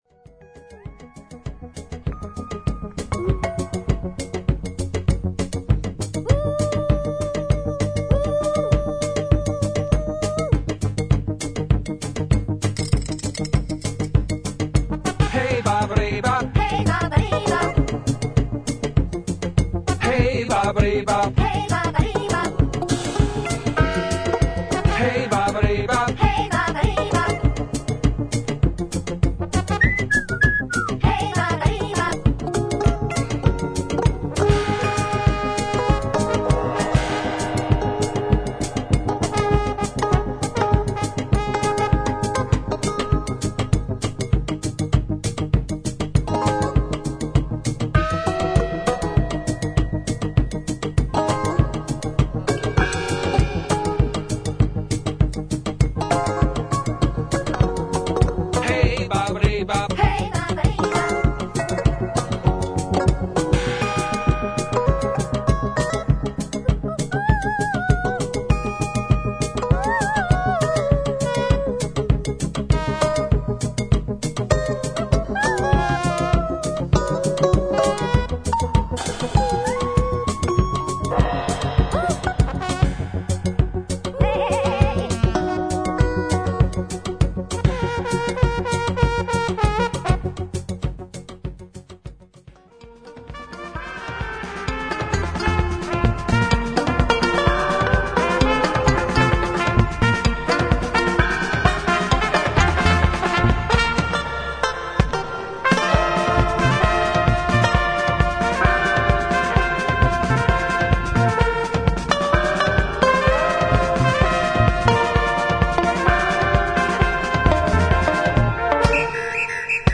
軽快なリズムにトロピカルなギターやホーンが乗るエキゾなダンス・チューン